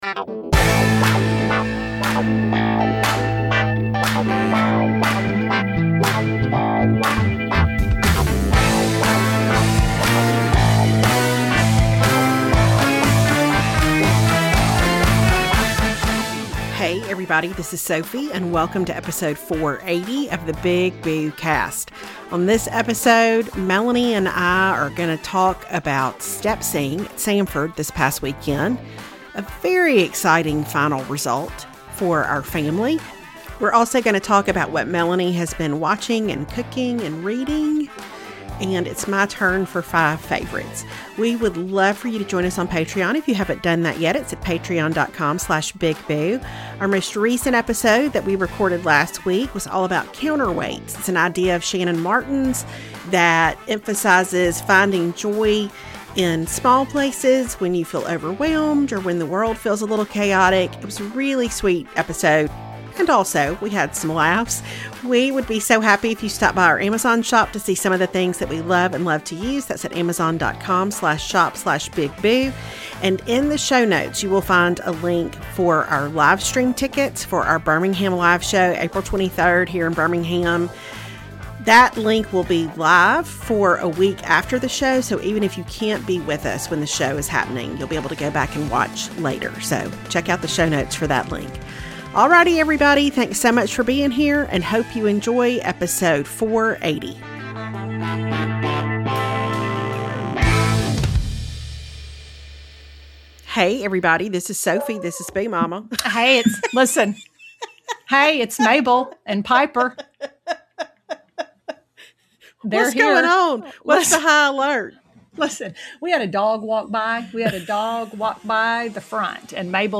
As always we deal with the low-level hum of a leaf blower (and a vacuum!), and it's my turn for Five Favorites.